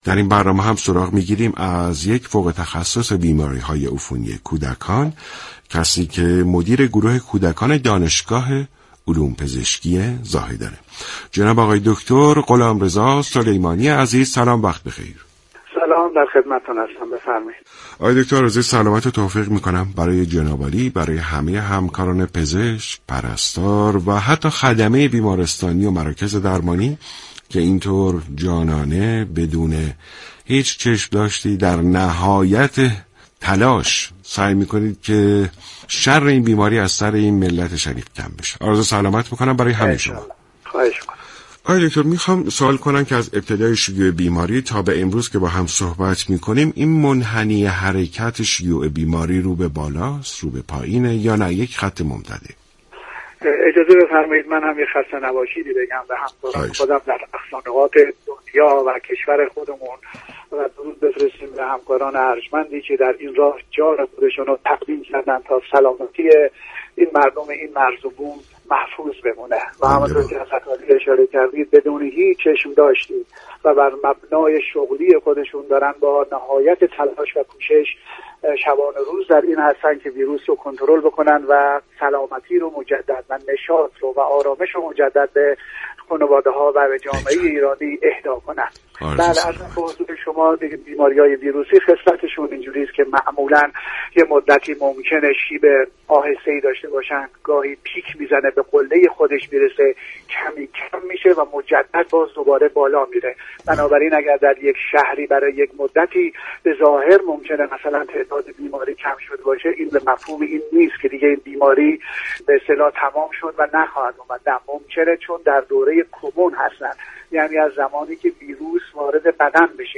شما می توانید از طریق فایل صوتی ذیل شنونده بخشی از برنامه سلامت باشیم رادیو ورزش كه شامل صحبت های این متخصص بیماری های عفونی درباره كرونا است؛ باشید.